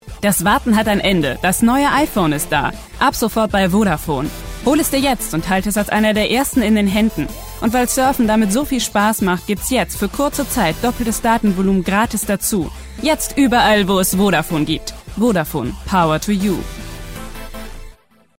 Eigenes prof. Studio vorhanden (Neumann TLM 103)
Sprechprobe: Werbung (Muttersprache):